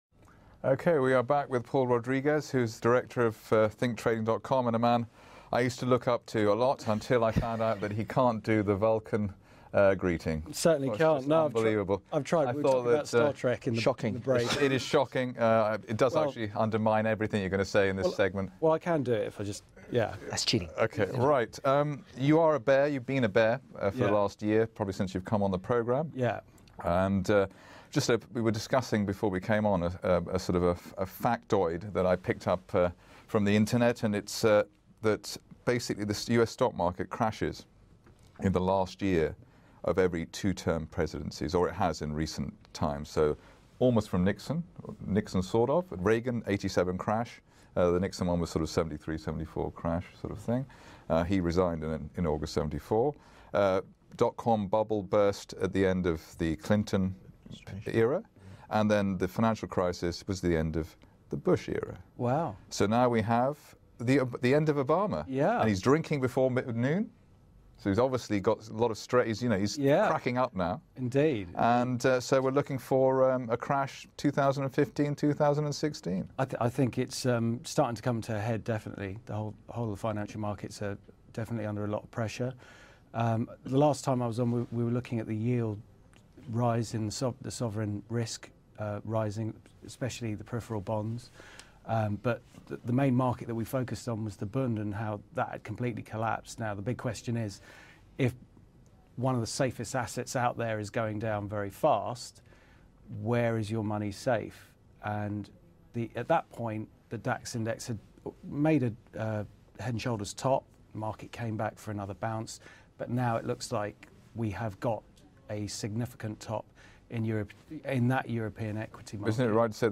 a bears corner discussion